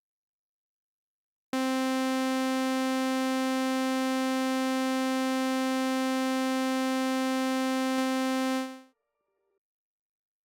Quick check here. by lack of sine wave I used a saw wave from Analog Keys.
long note in sequencer, started recording on 505 when note was already playing.
then I started recording the 505 output in Ableton live, and then I hitte play on the AR which makes the 505 start too. here is the clip: